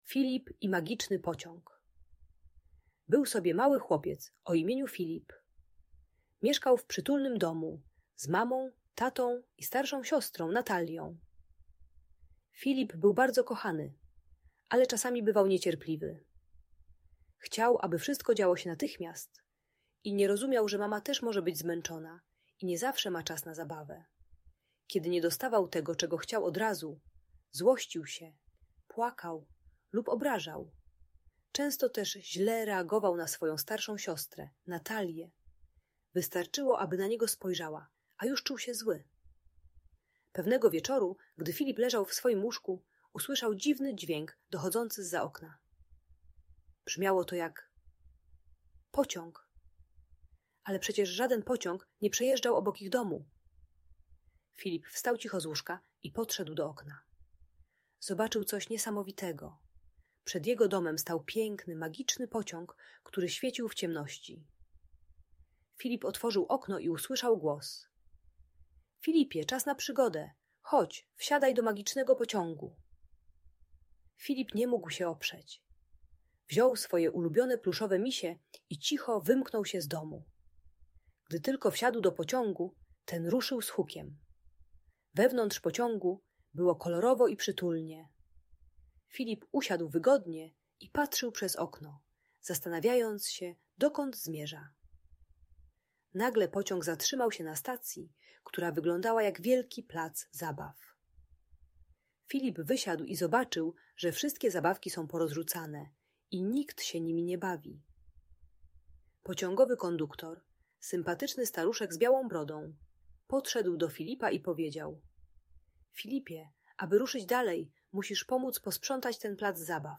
Filip i magiczny pociąg - opowieść o cierpliwości - Audiobajka